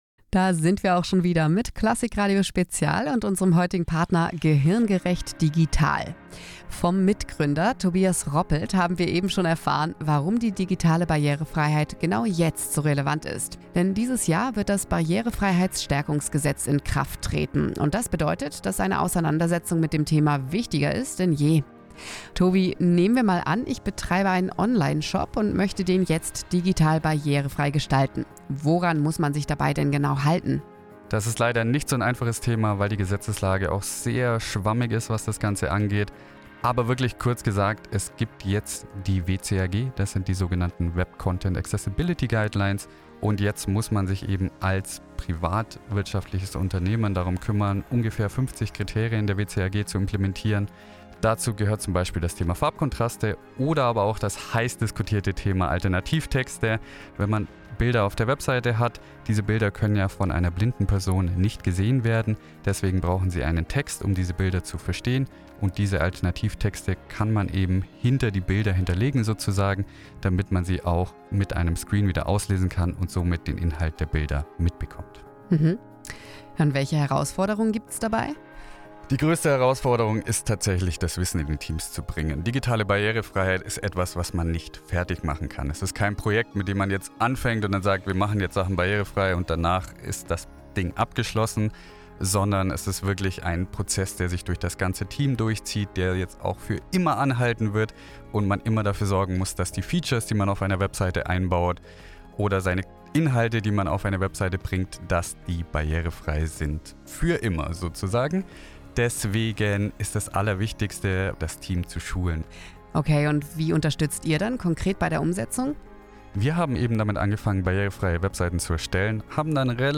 Wir waren zu Gast in der Klassik-Radio-Spezial-Sendung über die digitale Barrierefreiheit.
Interview